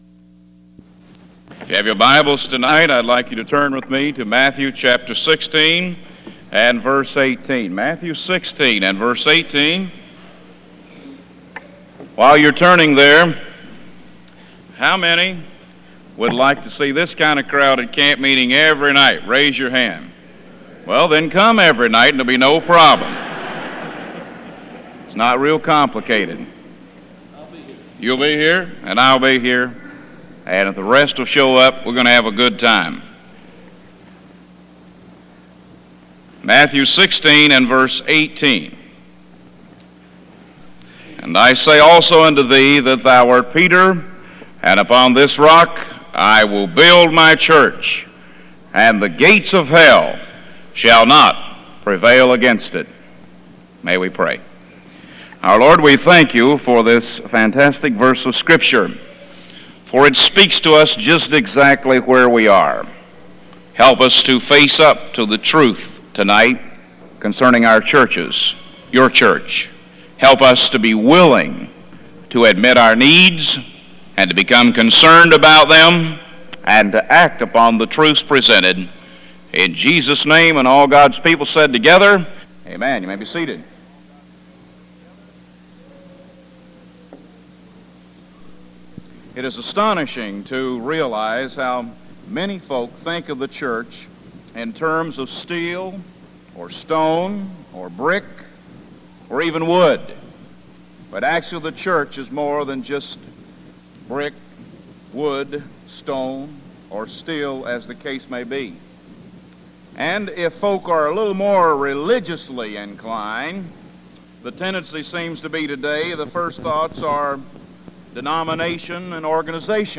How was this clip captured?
Houghton Bible Conference 1982